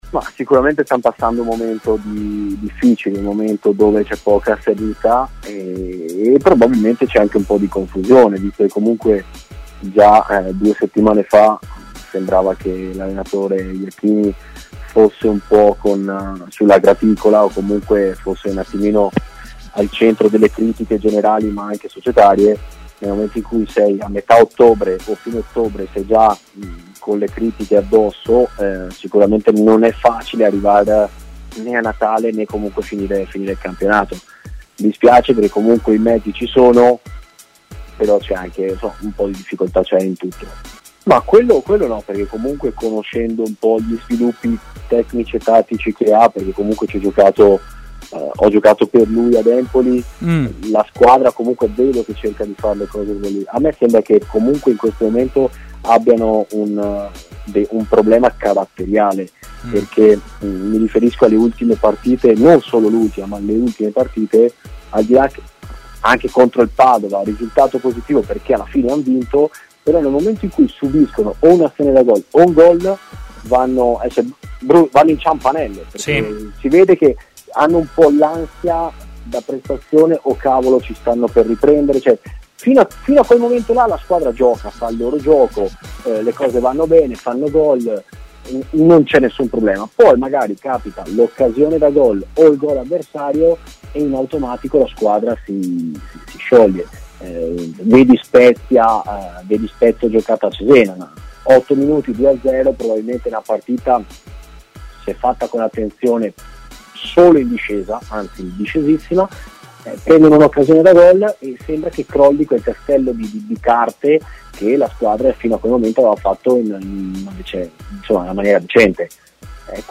Ecco le sue parole a partire dal suo ex allenatore Iachini: "Sta passando un momento difficile, e già da un paio di settimana l'allenatore sembrava in discussione.